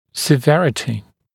[sɪ’verətɪ][си’вэрэти]серьезность, тяжесть (напр. патологии)